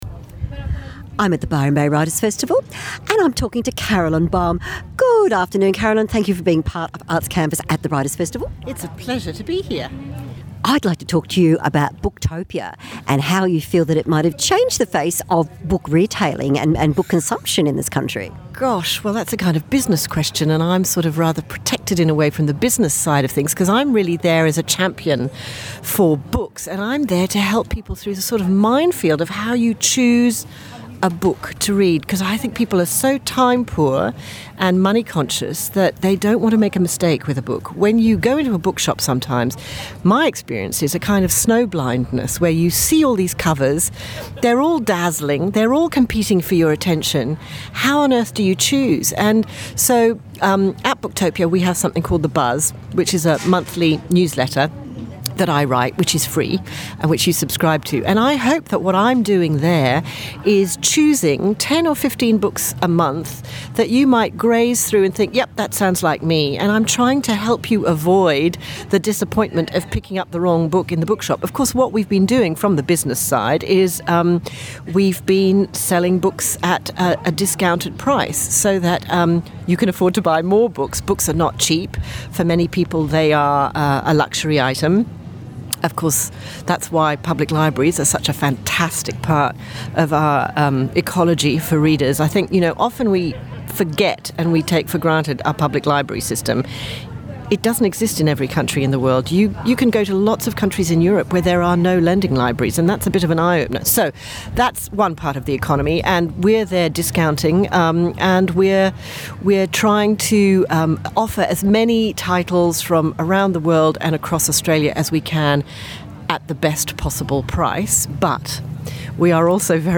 Books and Authors - live interviews
Recorded at Byron   Writers Festival 2015